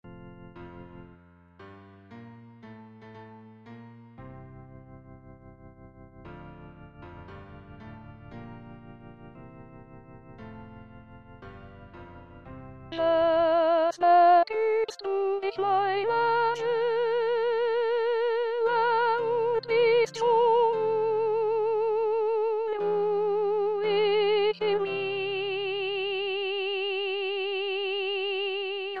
Introduction 5 voix, mes.  1-13